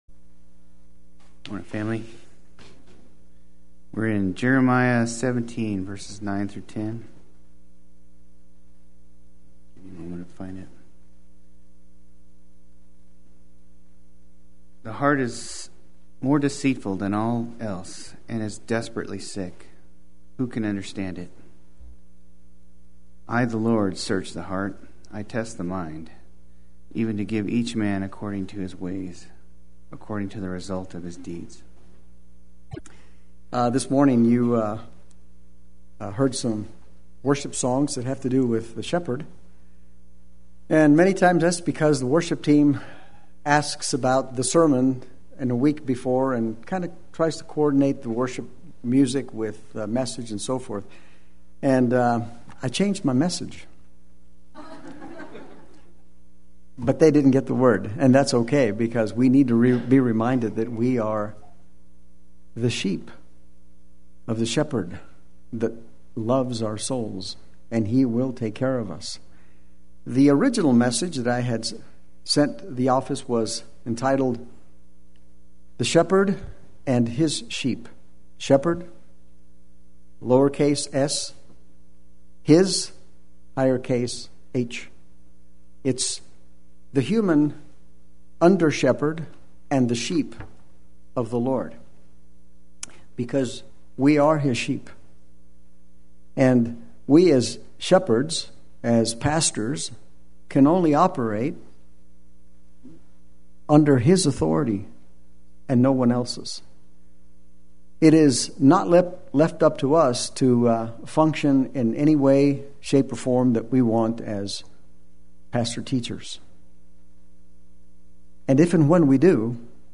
Play Sermon Get HCF Teaching Automatically.
The Heart of the Matter is the Heart of the Matter Sunday Worship